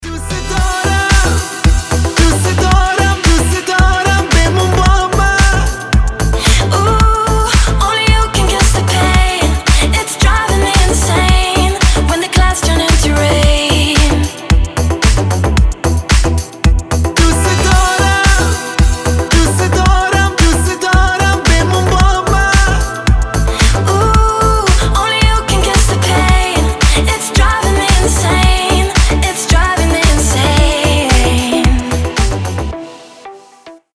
• Качество: 320, Stereo
мужской вокал
женский вокал
deep house
восточные мотивы
Club House
дуэт